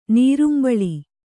♪ nīrumbaḷi